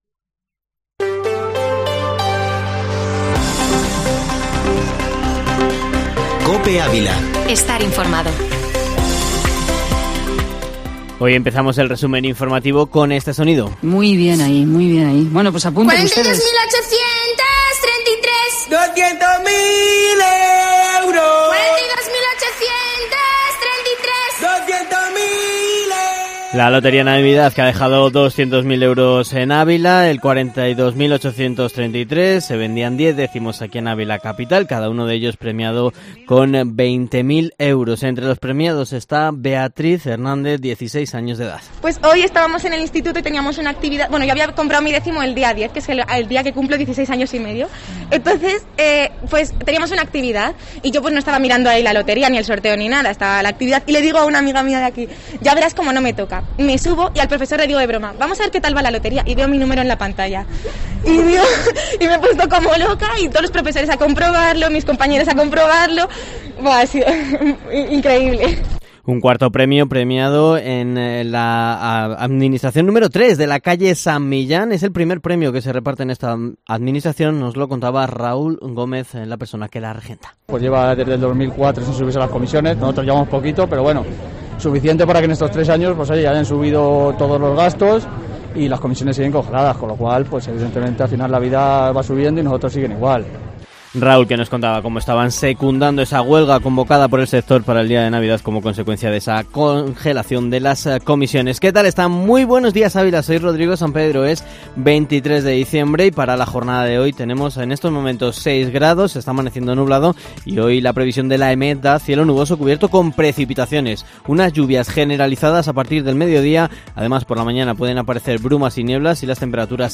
Informativo Matinal Herrera en COPE Ávila -23-dic